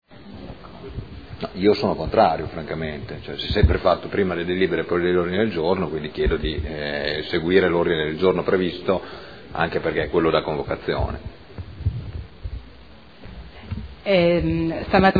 Seduta del 6 marzo. Proposta di modifica dell'odg.